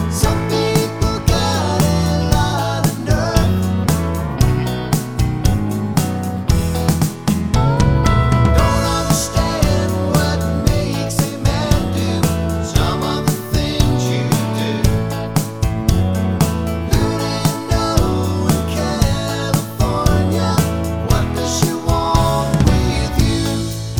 no Backing Vocals Country (Male) 3:04 Buy £1.50